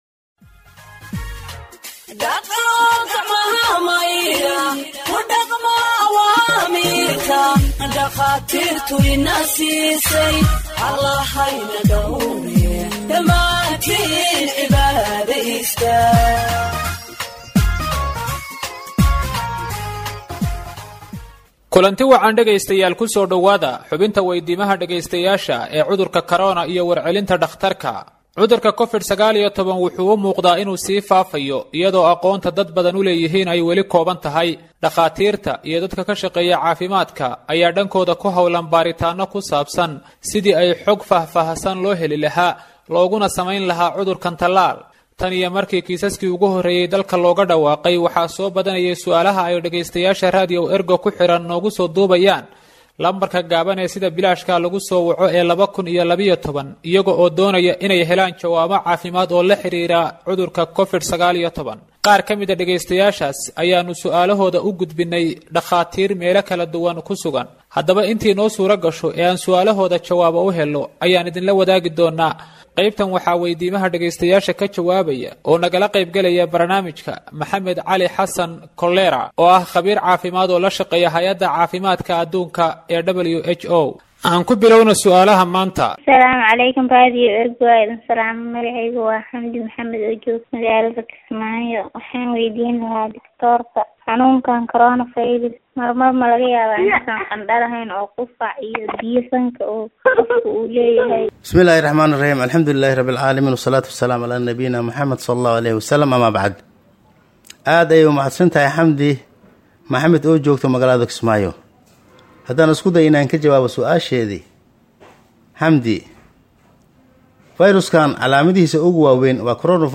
Health expert answers listeners’ questions on COVID 19 (25)